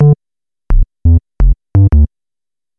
Loops de baixo 42 sons